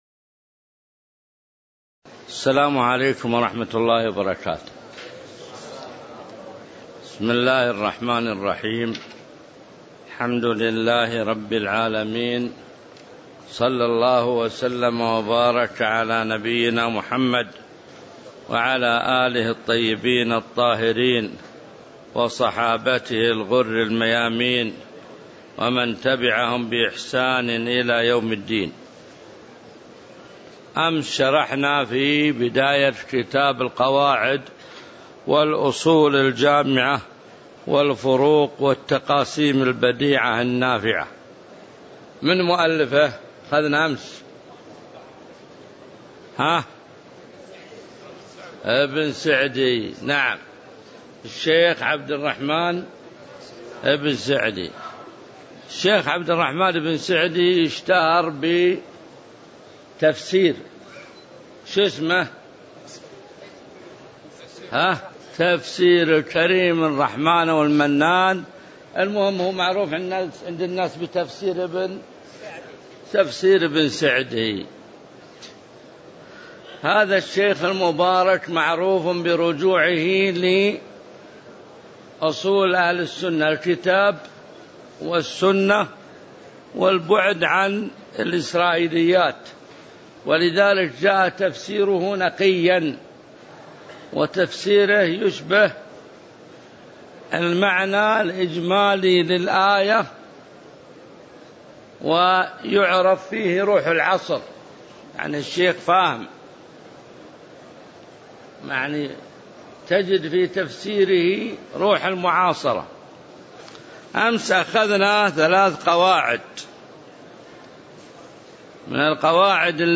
تاريخ النشر ١١ جمادى الآخرة ١٤٣٨ هـ المكان: المسجد النبوي الشيخ: معالي الشيخ د. عبدالله بن محمد المطلق معالي الشيخ د. عبدالله بن محمد المطلق من قوله: قاعدة الوجوب يتعلق بالاستطاعه (02)